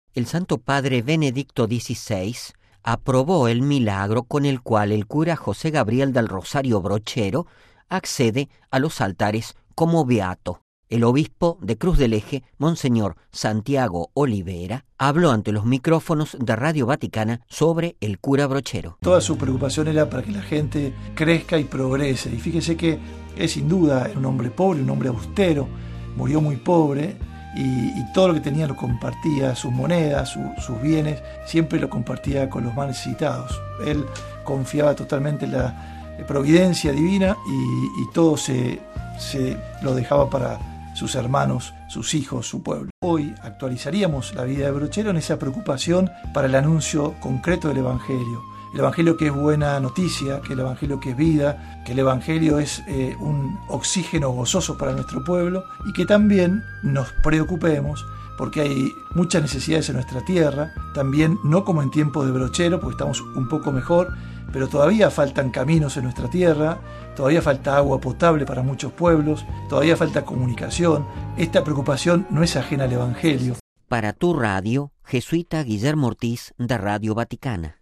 (RV).- (Con audio) El Santo Padre Benedicto XVI aprobó el milagro obtenido por intercesión de José Gabriel del Rosario Brochero que así sube a los altares como beato. Monseñor Santiago Olivera, Obispo de Cruz del Eje, Córdoba, Argentina, habló ante los micrófonos de Radio Vaticana sobre el Cura Brochero: RealAudio